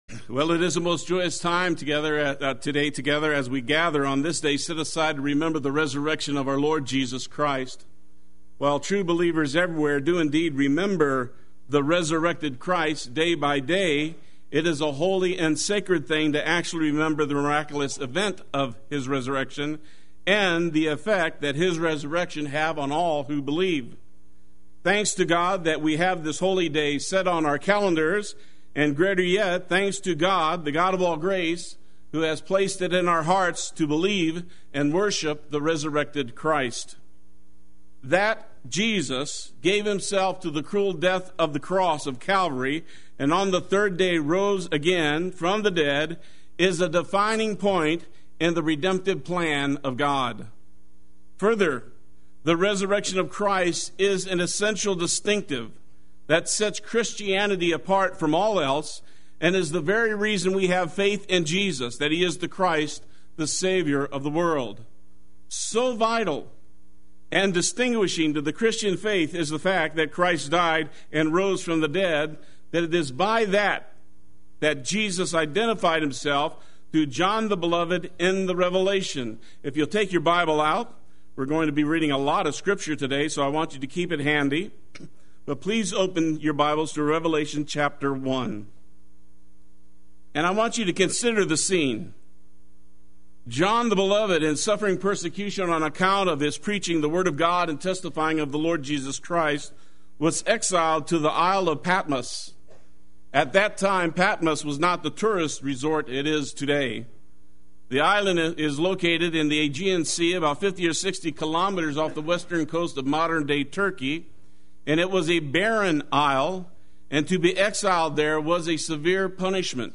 Play Sermon Get HCF Teaching Automatically.
I Am Alive Forevermore Sunday Worship